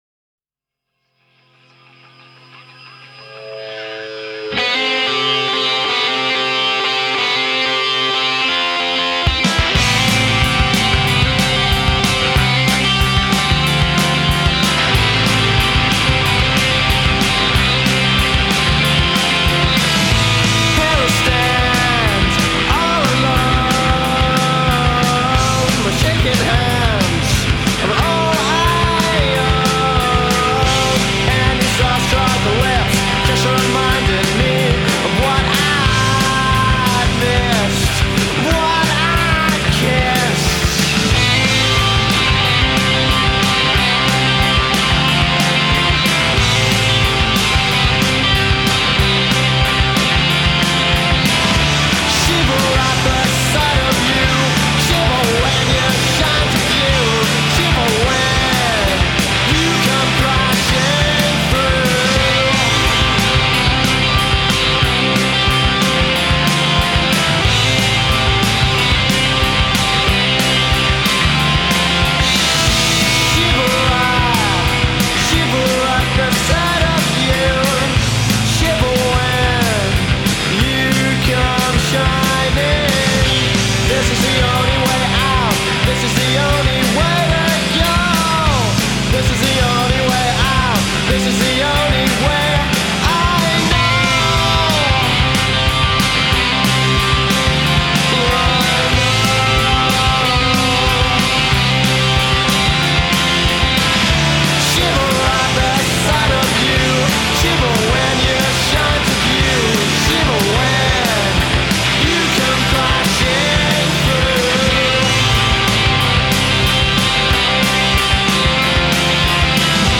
rock/pop side of the Shoegaze genre